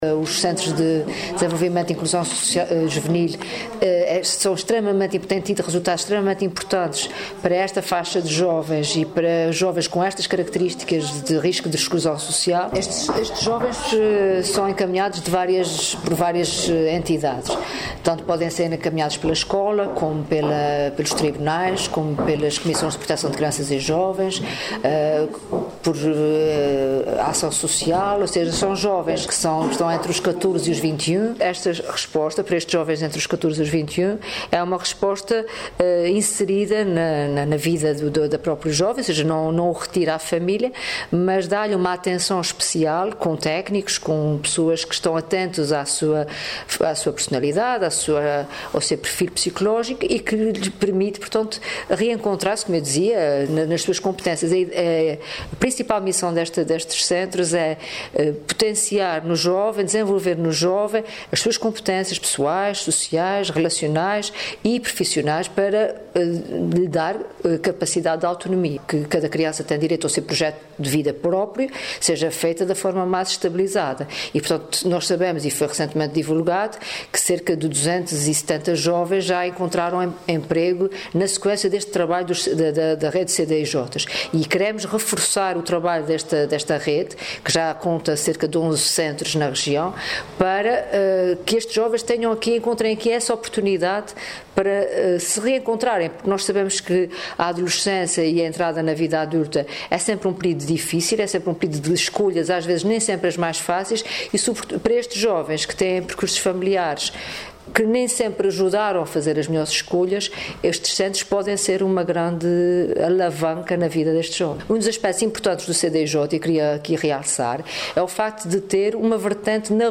Piedade Lalanda, que falava no final de uma visita ao CDJI do Instituto de Apoio à Criança, em Ponta Delgada, afirmou ainda que a intenção do Executivo açoriano passa também por incrementar a promoção de projetos de vida facilitadores da sua inserção sócio-familiar, escolar e profissional.